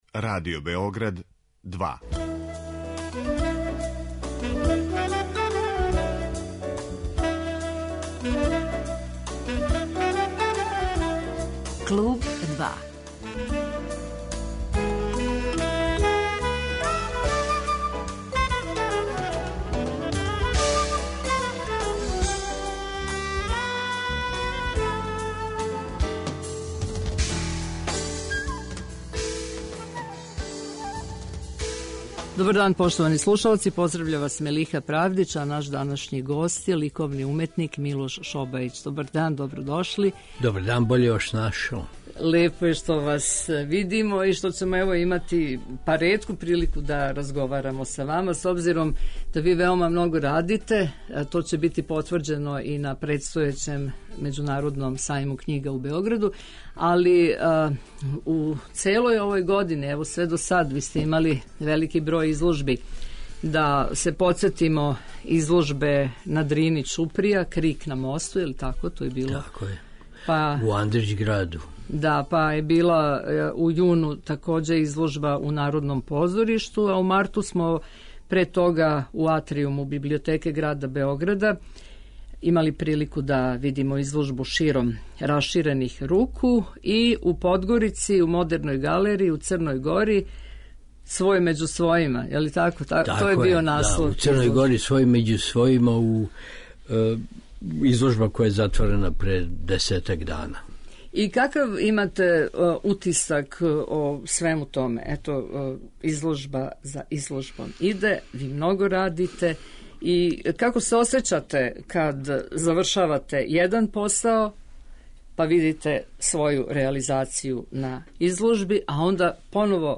Гост 'Клуба 2' је ликовни уметник Милош Шобајић